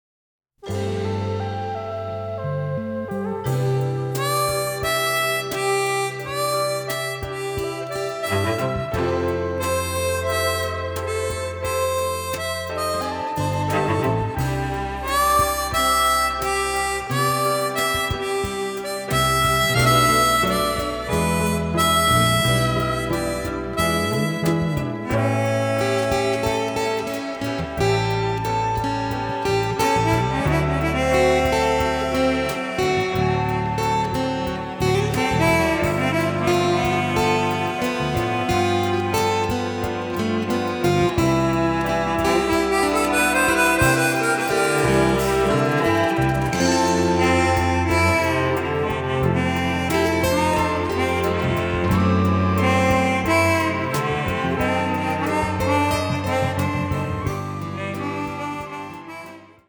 trumpeter
cellist
in a luminous and captivating style